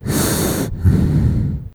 gas_mask_middle_breath5.wav